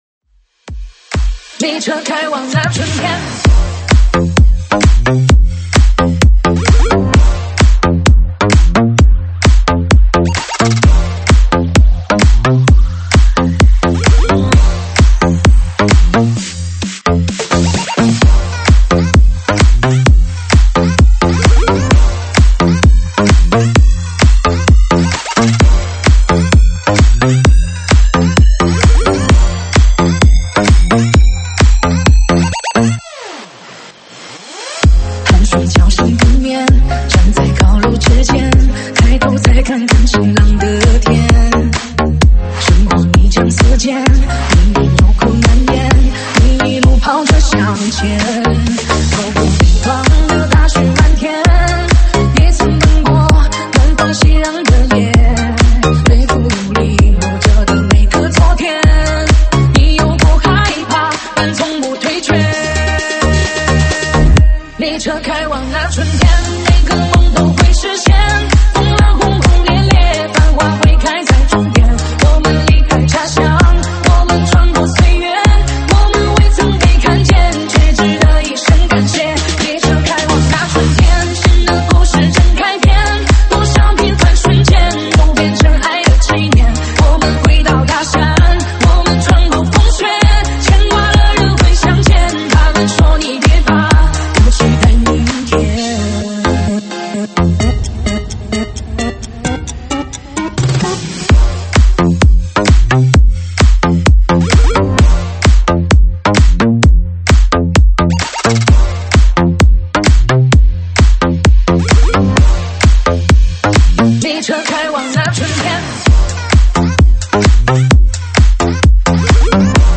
舞曲类别：独家发布